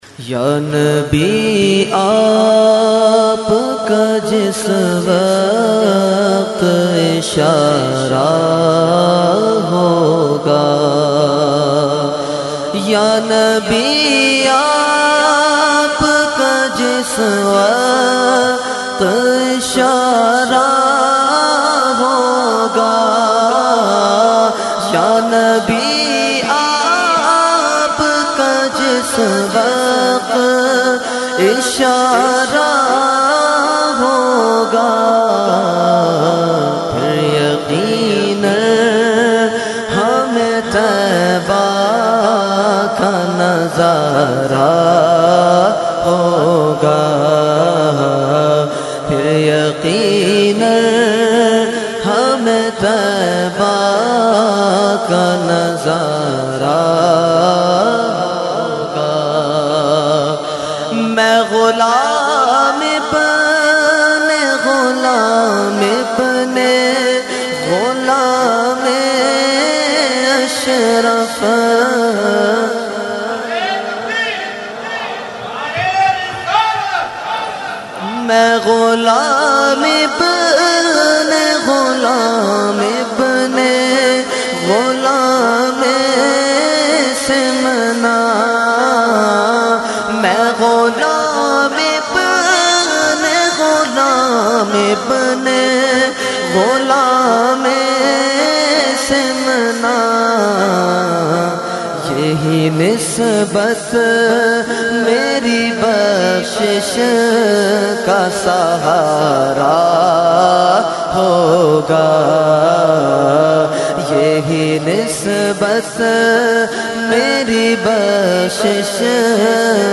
Category : Naat | Language : UrduEvent : Khatam Hizbul Bahr 2019